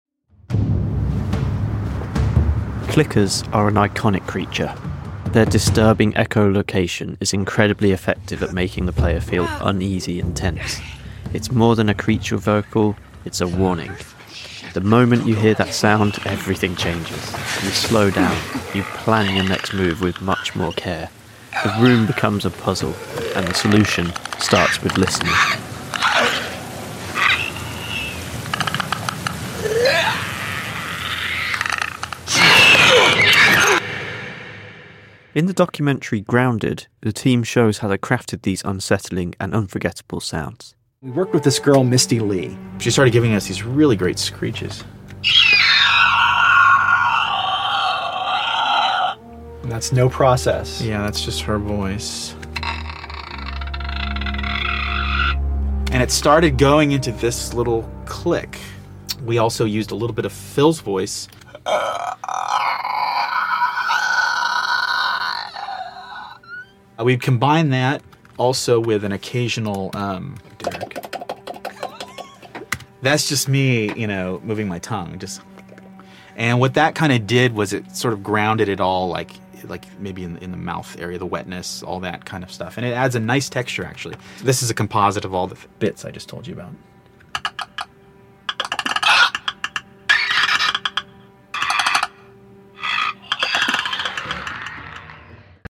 The Iconic Clickers in The Last of Us. Their disturbing echolocation is incredibly effective at making the player feel uneasy and tense.
Their sound design is deliberately erratic—offbeat, unpredictable, almost insect-like.